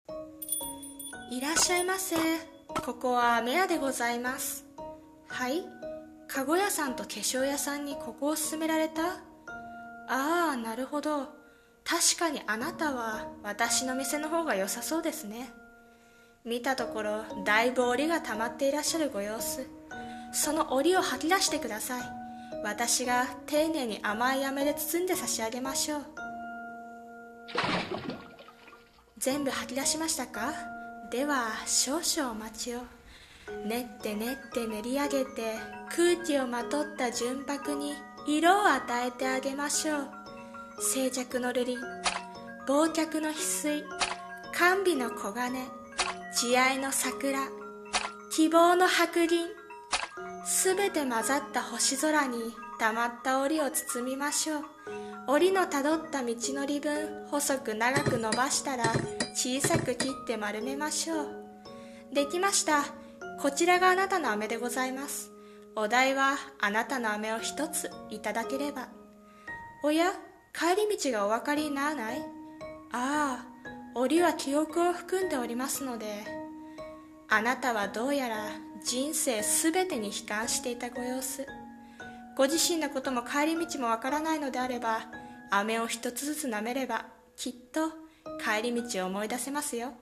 さんの投稿した曲一覧 を表示 【一人声劇】『飴屋～招転街（しょうてんがい）〜』